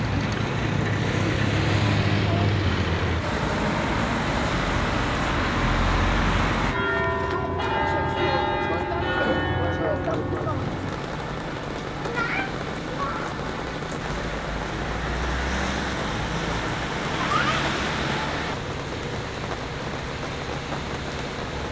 Here’s a clip that contains both the label Church_bell and Traffic_noise_and_roadway_noise: